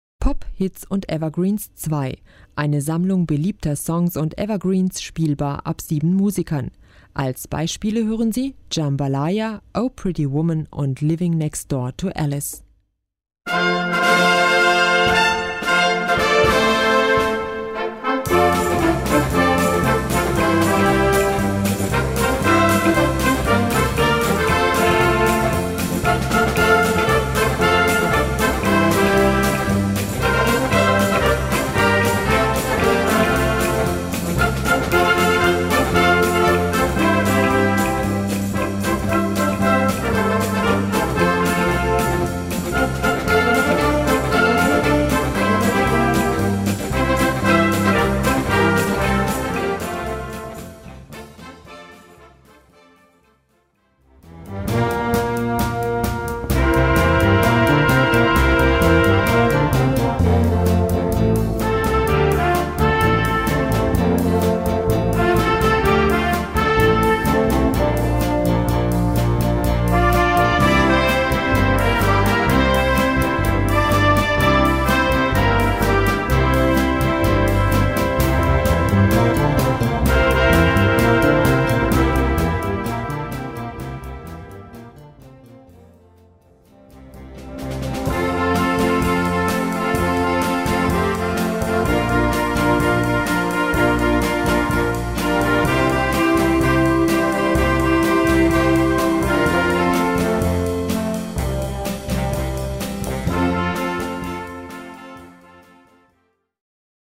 Gattung: Sammelausgabe
Besetzung: Blasorchester
melodische und mitreißende Arrangements